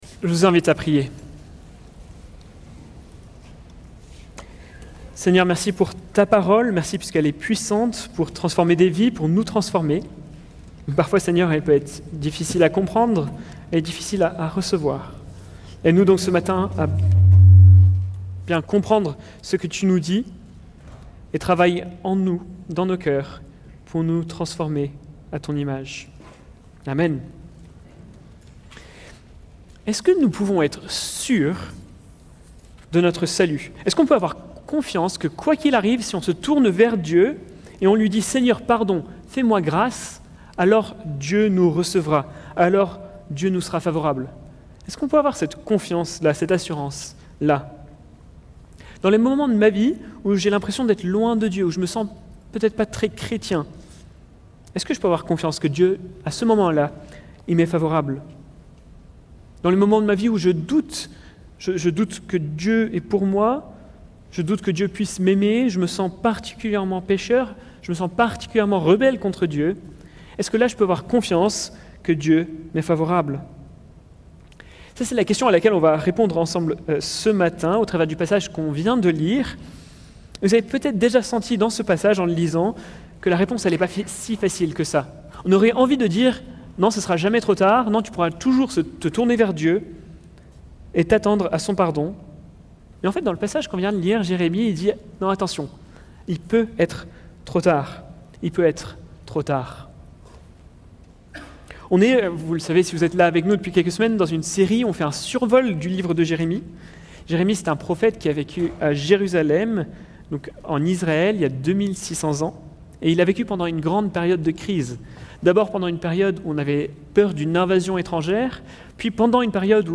Plan de la prédication : Contexte – La sècheresse spirituelle 1. Les faux prophètes ne feront pas l’affaire 2. Le vrai prophète ne fera pas...